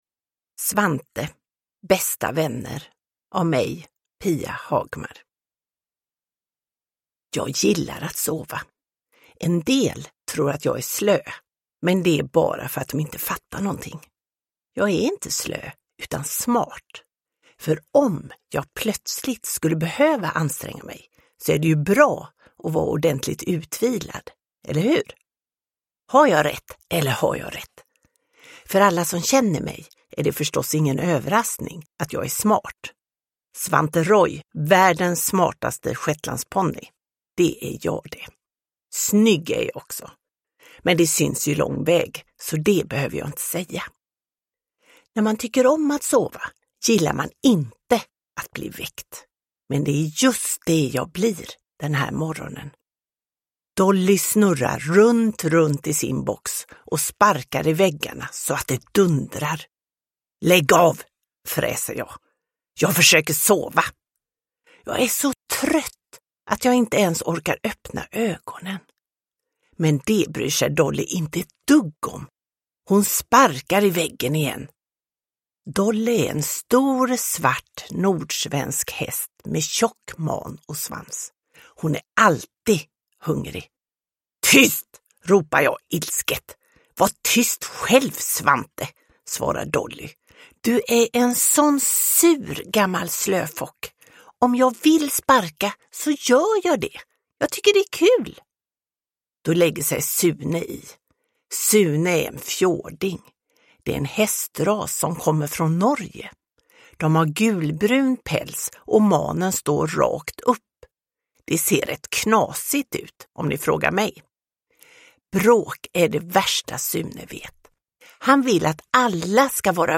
Bästa vänner – Ljudbok – Laddas ner